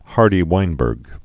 (härdē-wīnbûrg)